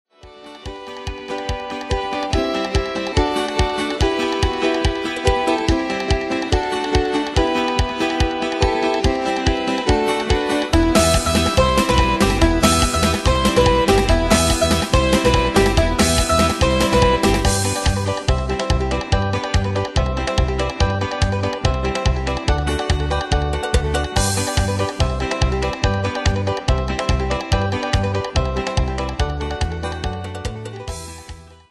Demos Midi Audio
Danse/Dance: Folk Cat Id.
Pro Backing Tracks